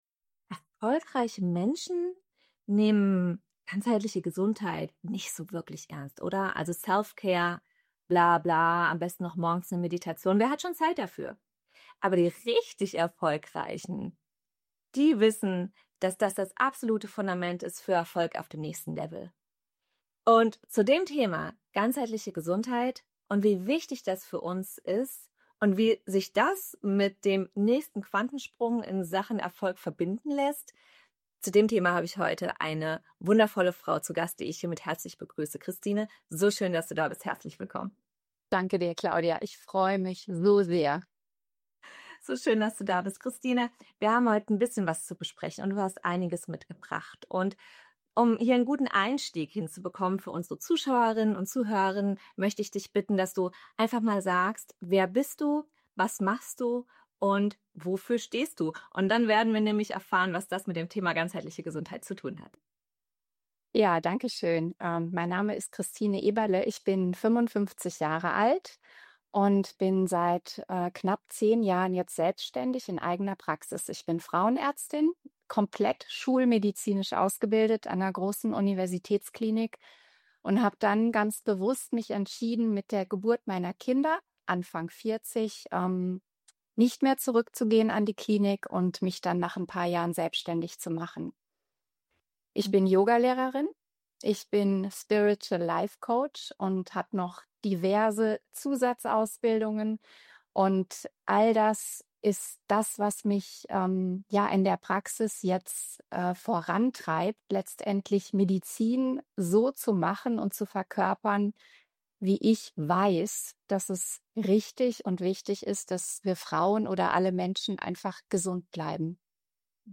Wir sprechen darüber, was ganzheitliche Gesundheit wirklich bedeutet, warum so viel Heilungspotenzial bereits in uns liegt und wie innere Balance, Klarheit und Körperbewusstsein die Basis für Erfolg auf dem nächsten Level sind. Ein ehrliches, klares Gespräch über Gesundheit, Selbstverantwortung und Führung von innen nach außen – für leistungsstarke Frauen, die keine schlechten Kompromisse mehr machen wollen.